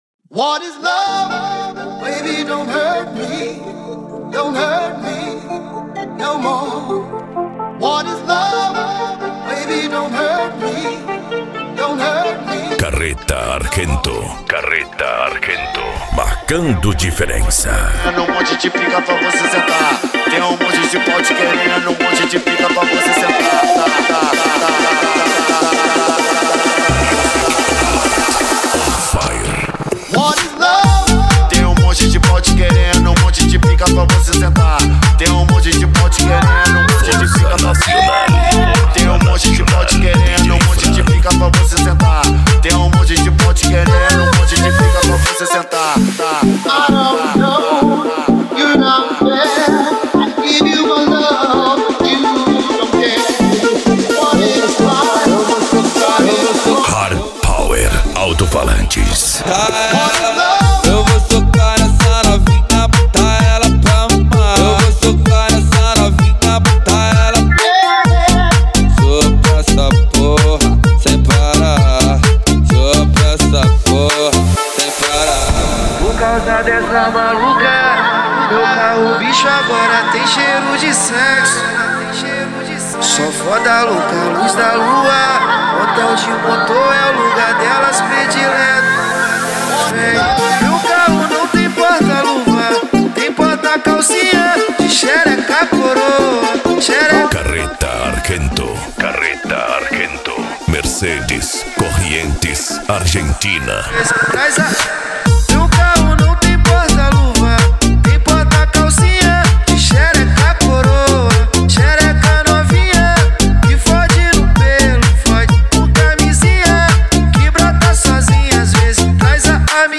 Bass
Euro Dance
Pagode
Remix